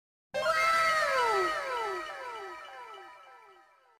Anime Wow Sound Button: Meme Soundboard Unblocked
Anime Wow